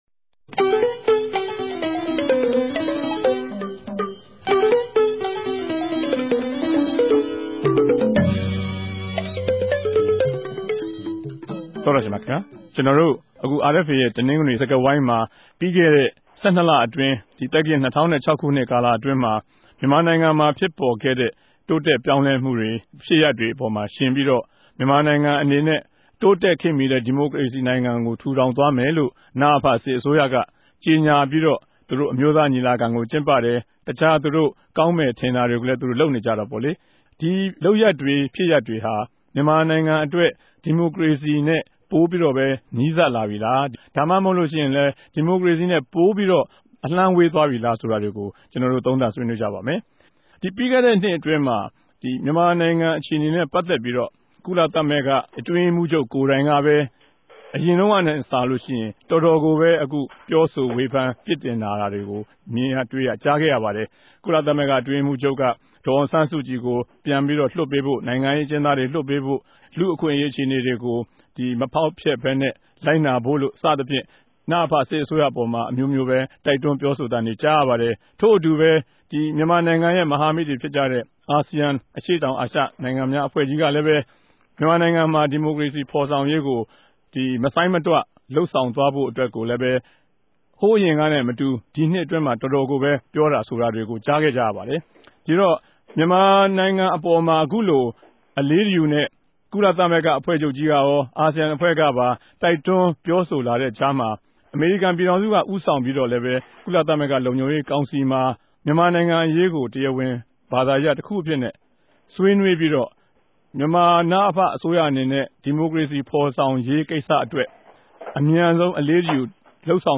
တပတ်အတြင်း သတင်းသုံးသပ်ခဵက် စကားဝိုင်း (၂၀၀၆ ဒီဇင်ဘာလ ၃၁ရက်)